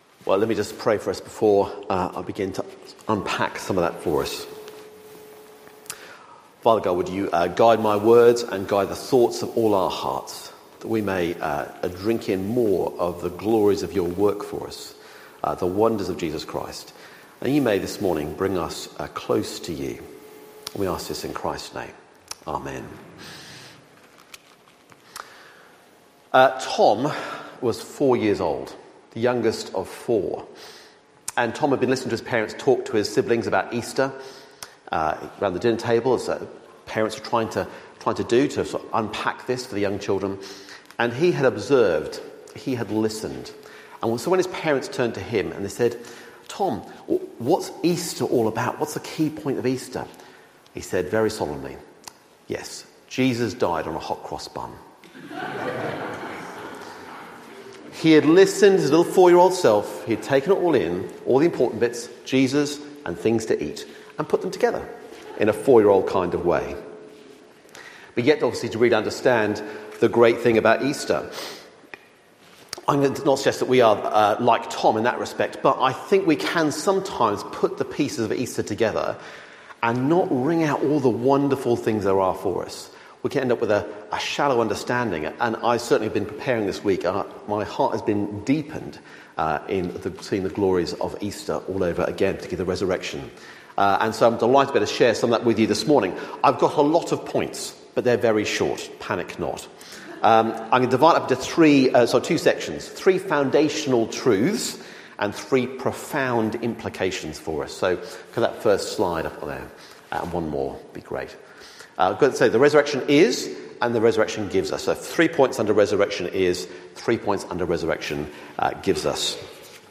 2026 Resurrection Preacher